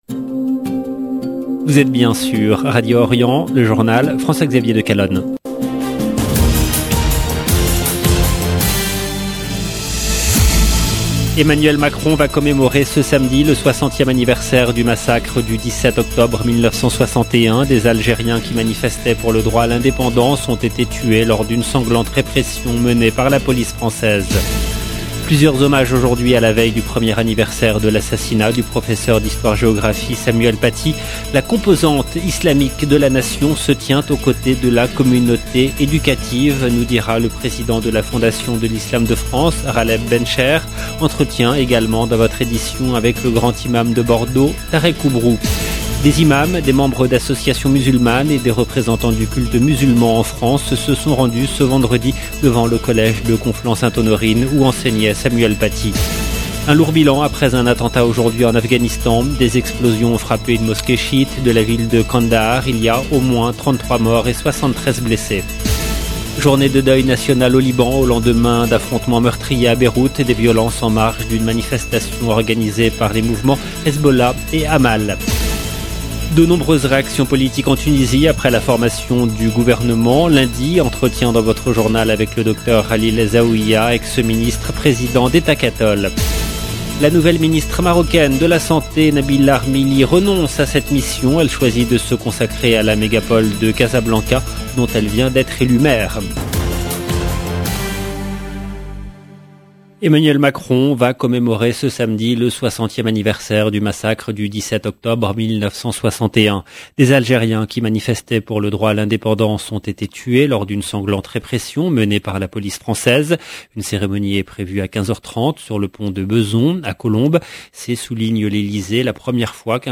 Entretien également dans votre édition avec le Grand imam de Bordeaux tareq Oubrou